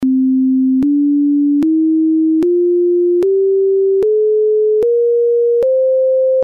Solution 2 : une division géométrique : entre les deux longueurs de cordes, un rapport constant : .